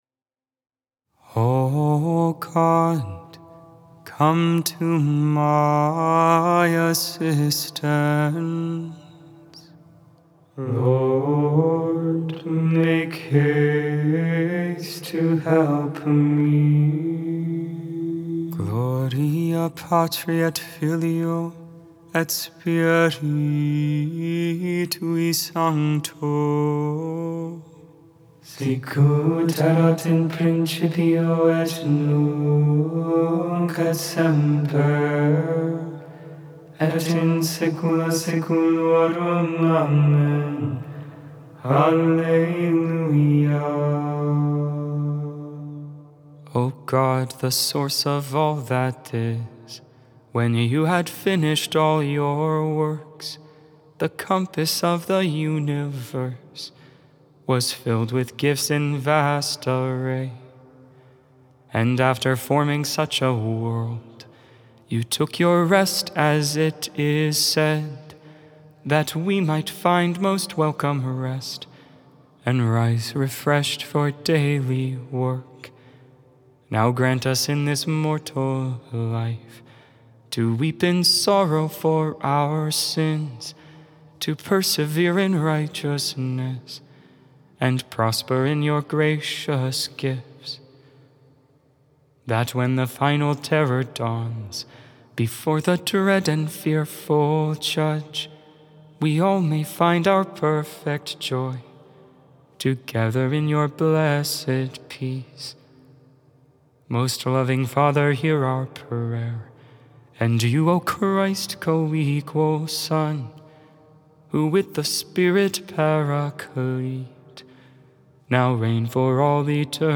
Join for morning (Lauds) and evening (Vespers) prayer. All Hymns, Psalms, and Prayer sung.